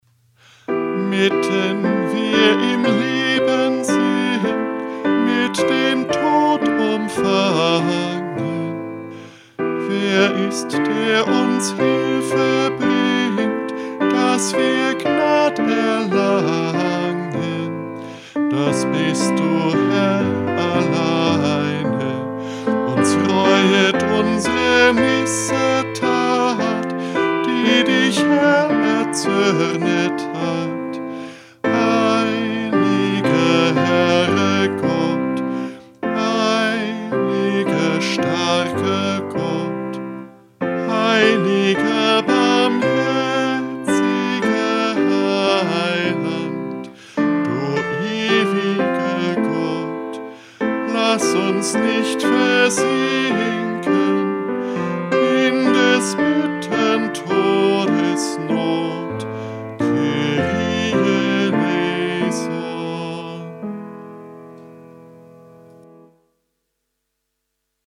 EG_518_Mitten_wir_im_Leben_sind_1.mp3